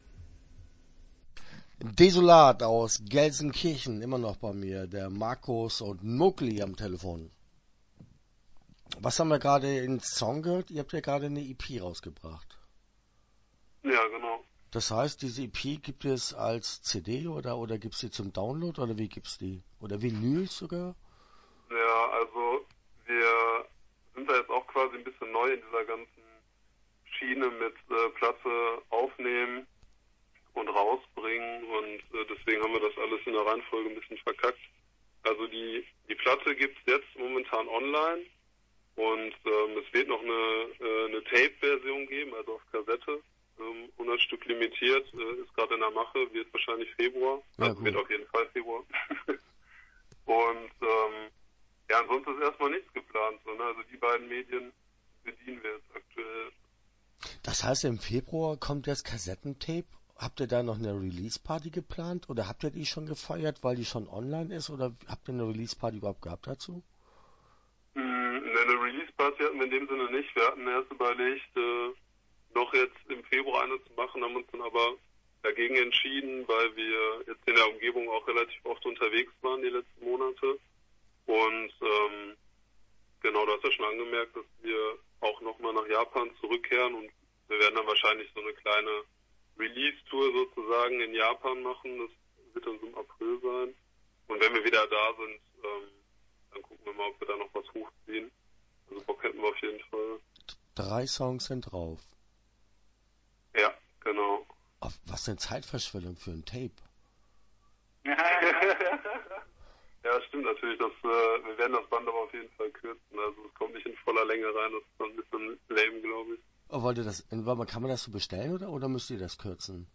Desolat - Interview Teil 1 (9:39)